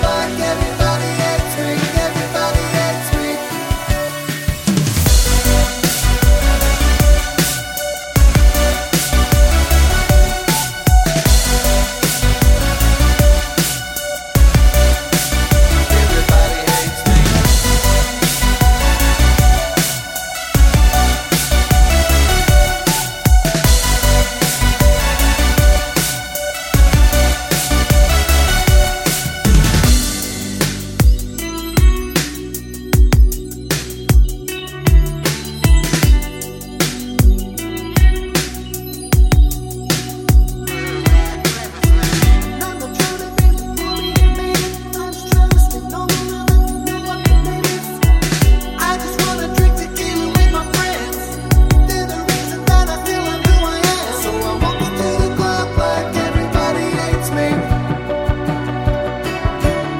explicit Pop (2010s) 3:43 Buy £1.50